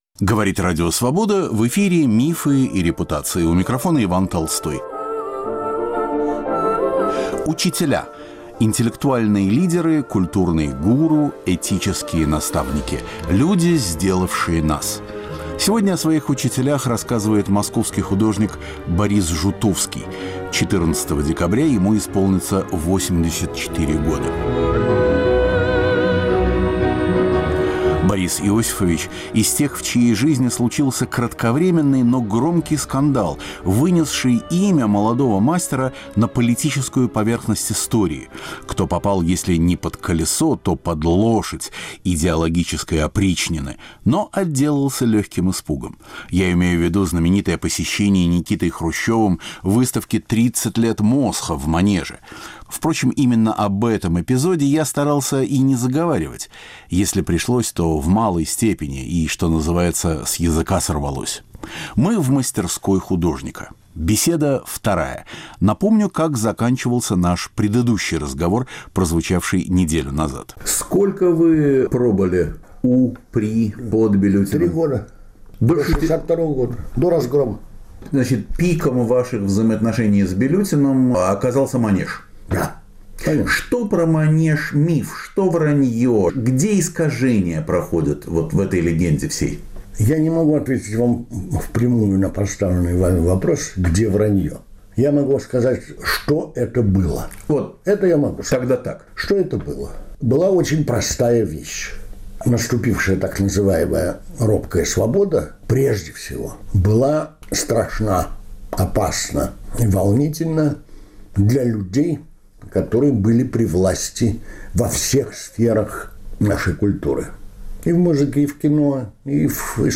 В серии "Учителя" - окончание разговора с московским художником Борисом Жутовским. Москва 1950-х, скандальная выставка в Манеже в 1962-м, дружба со Львом Разгоном и Даниилом Аниным.